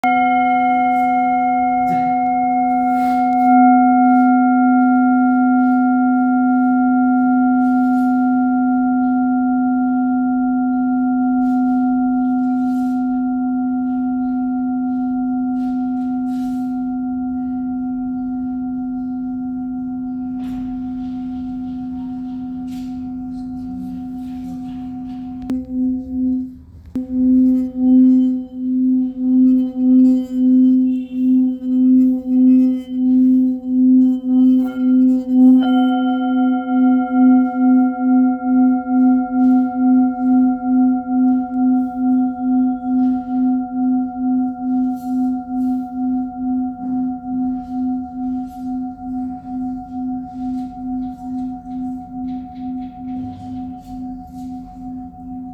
Handmade Singing Bowls-31553
Singing Bowl, Buddhist Hand Beaten, Antique Finishing, Select Accessories
Material Seven Bronze Metal